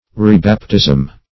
\Re*bap"tism\ (r[=e]*b[a^]p"t[i^]z'm)